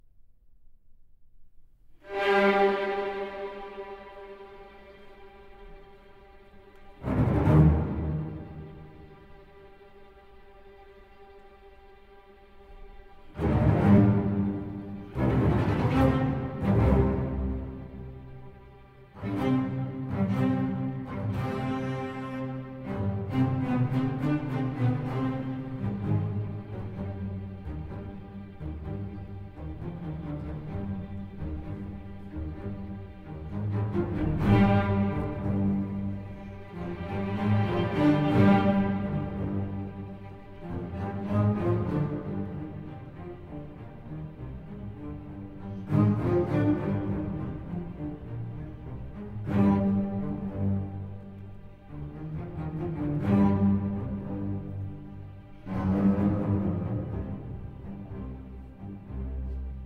Maestoso Allegro: اولین بخش این سمفونی، که معمولاً حدود 20 دقیقه طول می کشد، یک راهپیمایی تشییع جنازه را به تصویر می کشد که احساسات مربوط به مبارزه ی مرگ و زندگی را توصیف می کند. مشهورترین بخش آن احتمالاً شروع آن است که صدای آن حسی قدرتمند و شوم دارد.